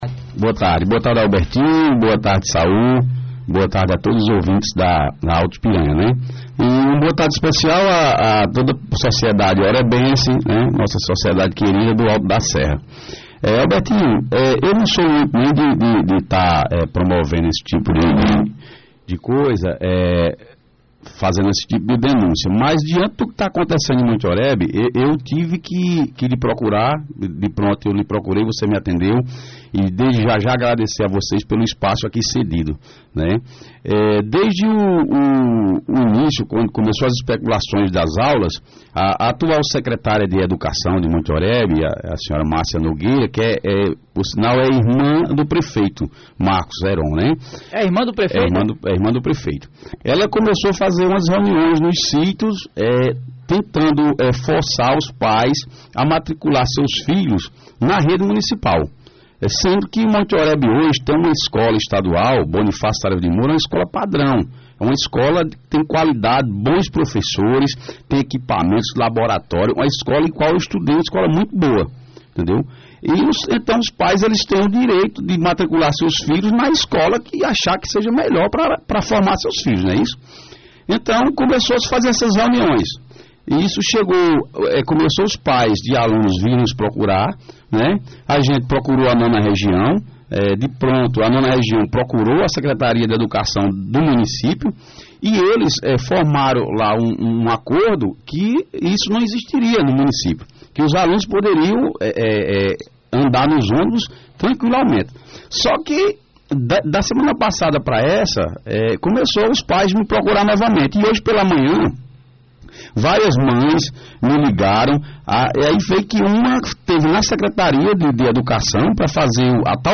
Ouça: A participação do ex – vice – prefeito Luciano Pessoa ontem no Rádio Vivo.